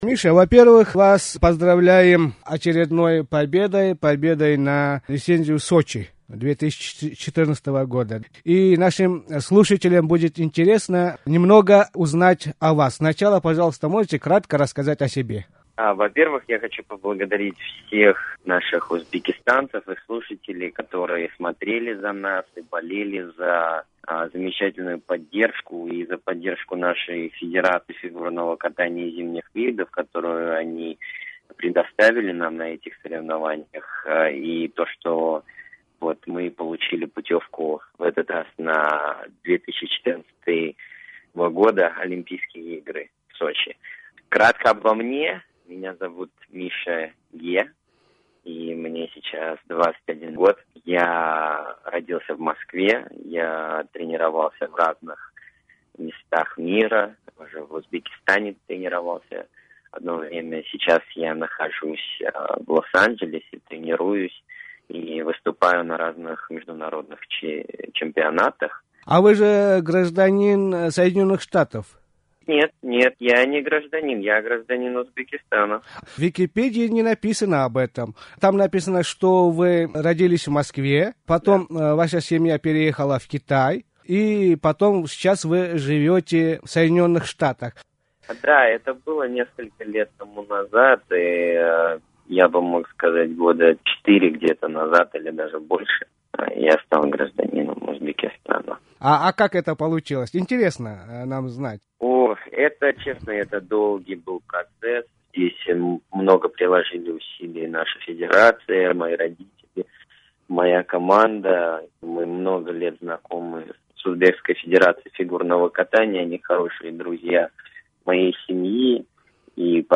Киритиш (Embed) Улашиш Миша Ге билан суҳбат билан Озодлик радиоси Киритиш (Embed) Улашиш Коддан алмашув буферингизга нусха кўчирилди.